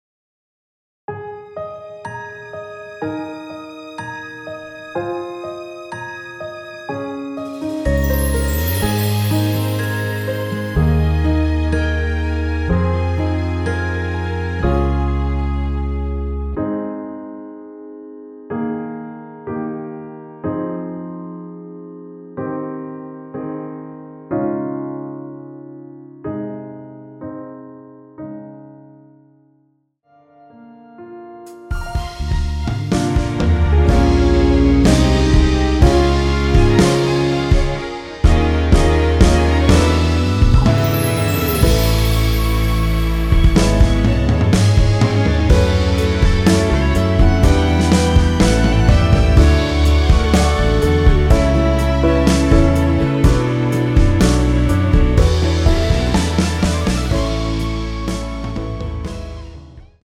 원키에서(+3)올린 MR입니다.
G#
앞부분30초, 뒷부분30초씩 편집해서 올려 드리고 있습니다.